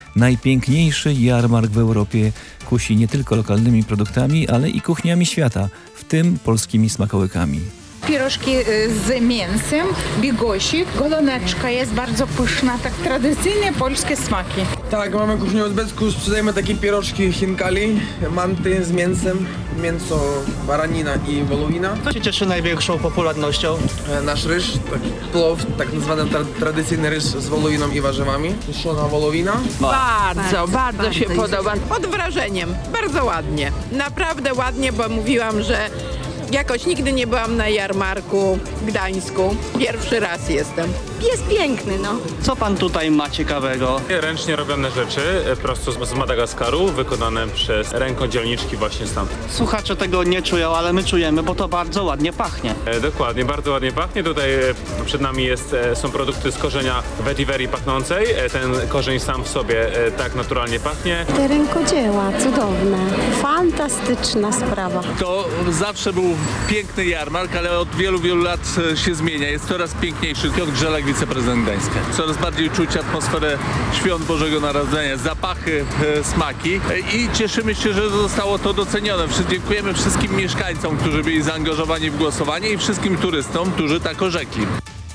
Zimowa potańcówka odbyła się w piątek na Jarmarku Bożonarodzeniowym w Gdańsku.
Posłuchaj materiału naszego reportera: https